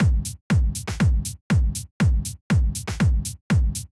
Drumloop 120bpm 07-C.wav